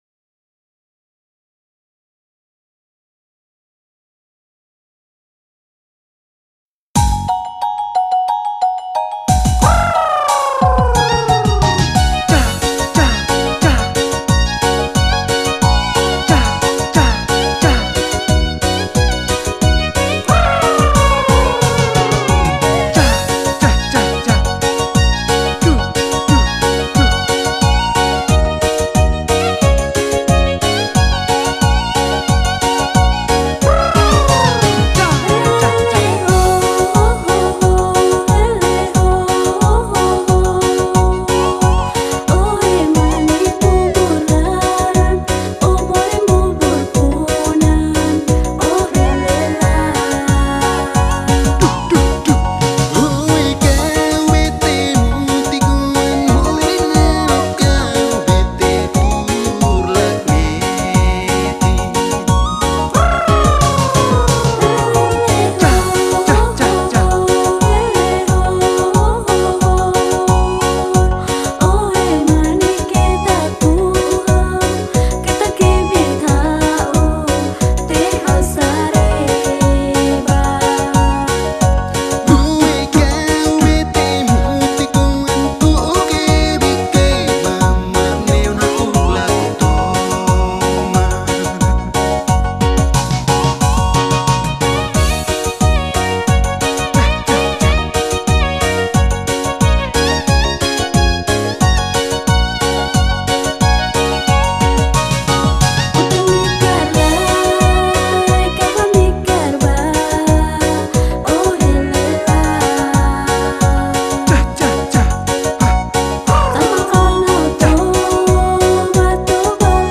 Es un baile tradicional de Timor, isla en la que se encuentra la ciudad de Atambúa.
TEBE MÚSICA BAILE COMPLETO